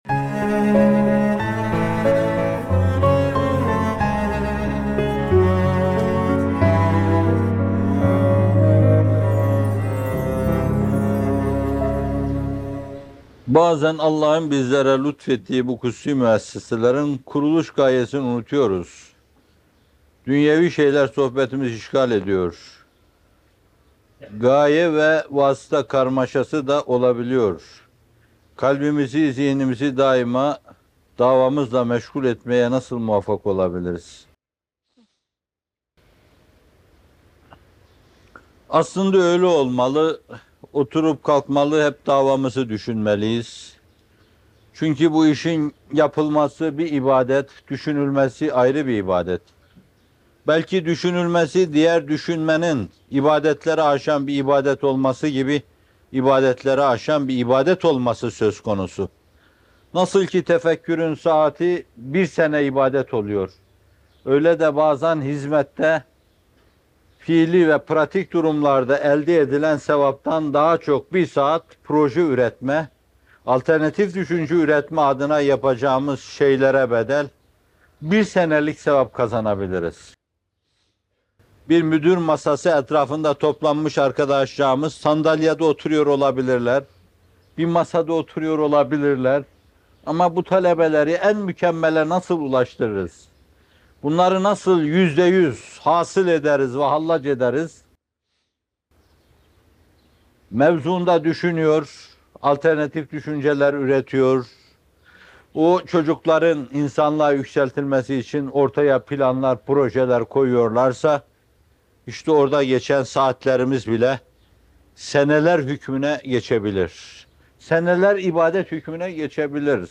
Mümin Budur! - Fethullah Gülen Hocaefendi'nin Sohbetleri